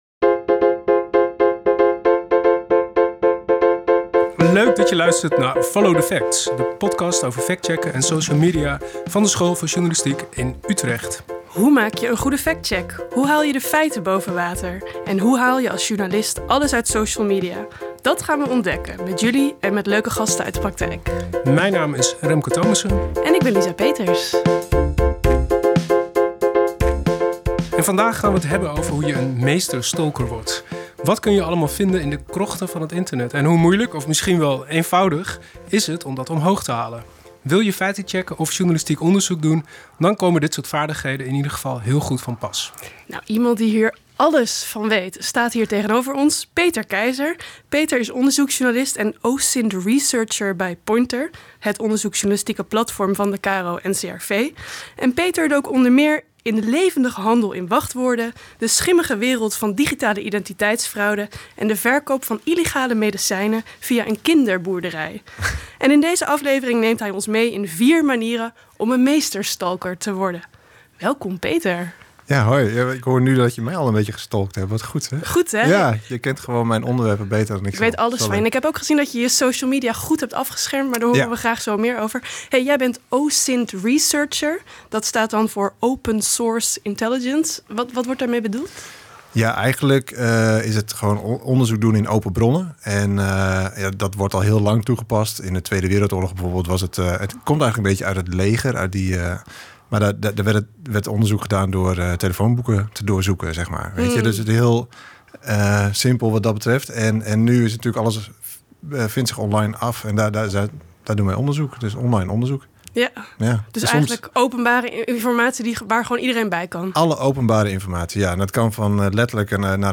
Jingles intro en outro